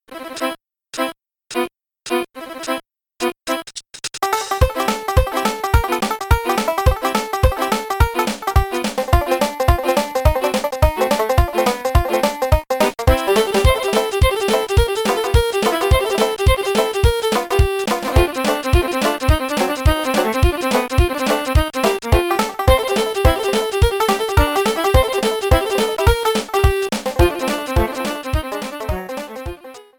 music theme for bayou race courses